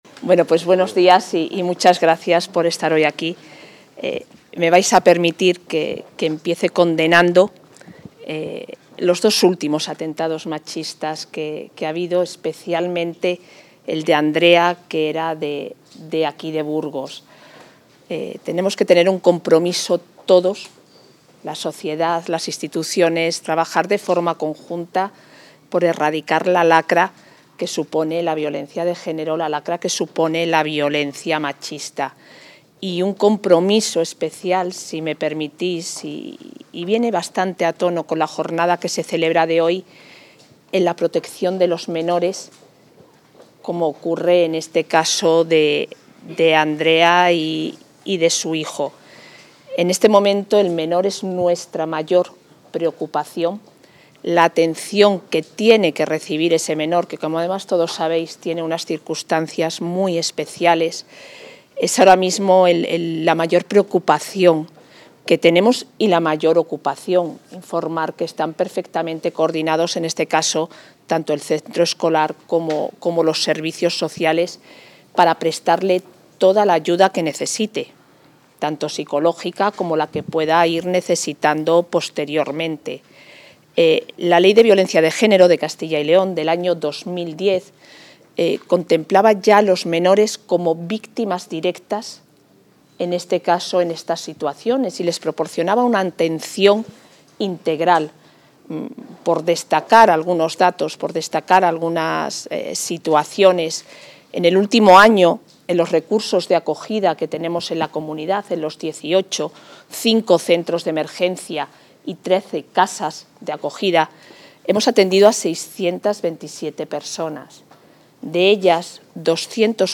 Intervención de la vicepresidenta.
La vicepresidenta de la Junta y consejera de Familia e Igualdad de Oportunidades participa en la jornada ‘Stop Violencia Vicaria’ celebrada en Burgos, donde ha destacado la prevalencia de la integridad del menor tanto en la legislación autonómica como en la Red de recursos de la Comunidad, así como el compromiso del Ejecutivo autonómico con la erradicación de esta forma de violencia de género.